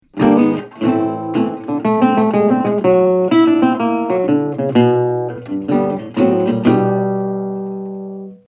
CHALLENGE: inspired by Eddie Lang's example, contestants will attempt to combine chords and single string fills over the four bar chord progression: Am/// D7/// G/// G///